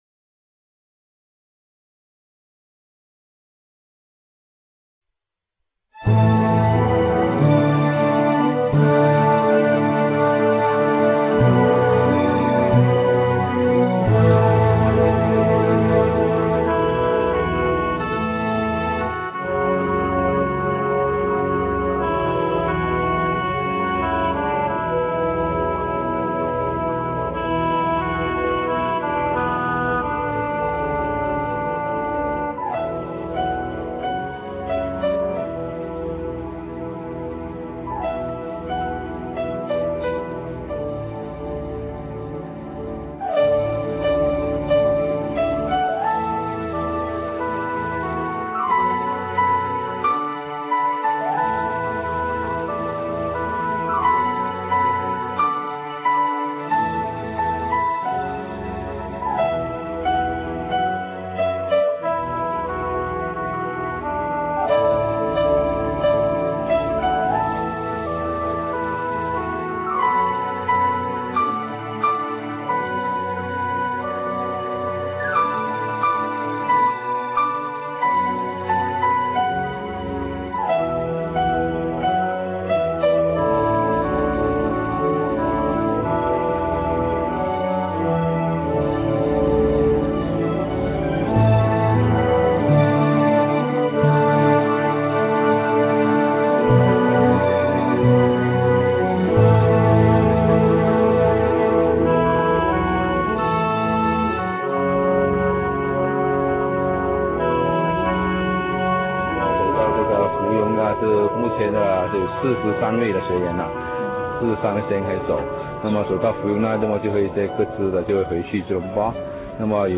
Hui_Gui_lu-Feb (music)_56kB.rm